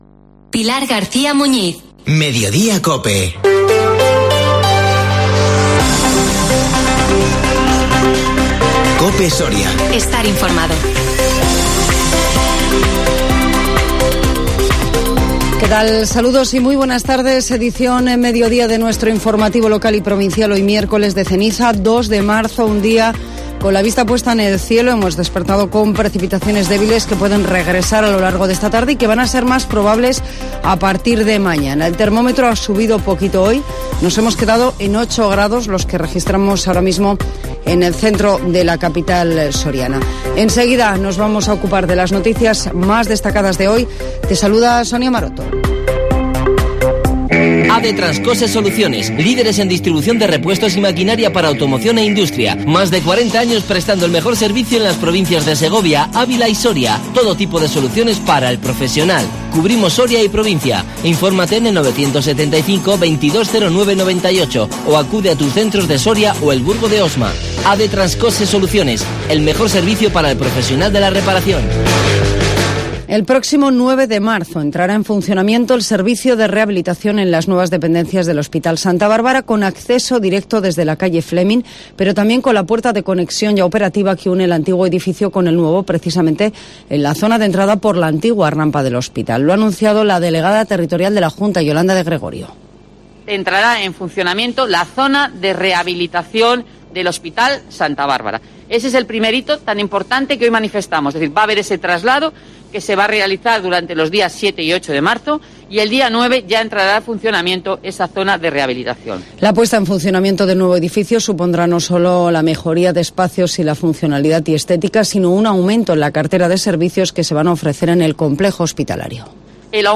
INFORMATIVO MEDIODÍA COPE SORIA 2 MARZO 2022